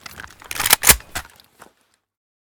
akm_unjam.ogg